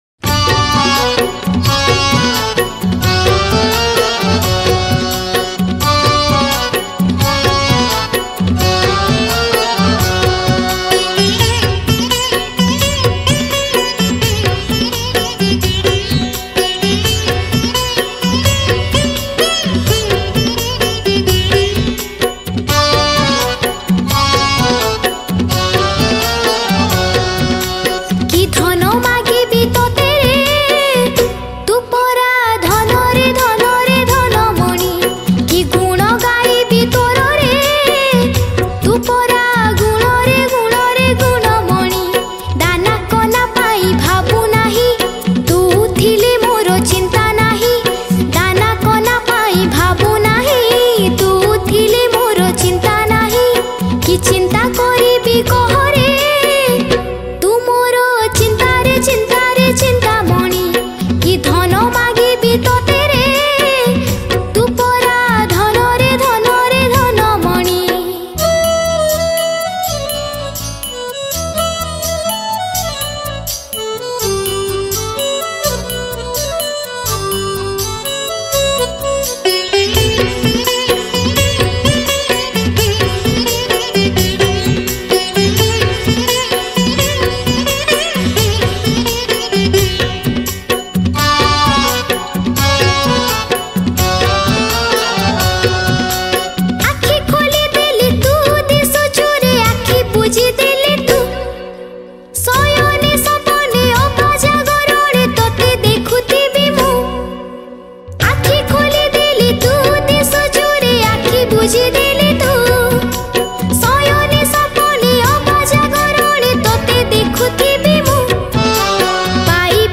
Odia Bhajan Song 2022